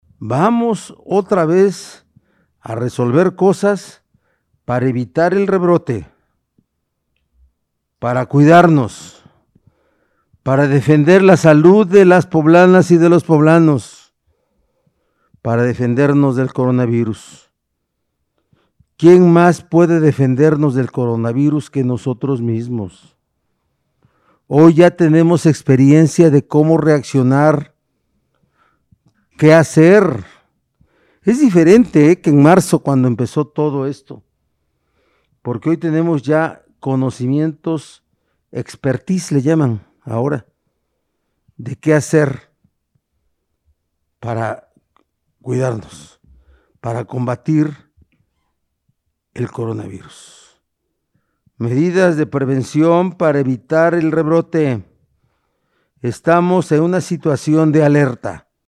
En videoconferencia de prensa en Casa Aguayo, el titular del Ejecutivo consideró que el estado está en una situación de alerta, por lo que es necesario tomar las acciones correspondientes para salvaguardar la salud de las y los poblanos, como seguir con el “Pacto Comunitario”, el uso de cubrebocas, el autoconfinamiento y no hacer reuniones sociales.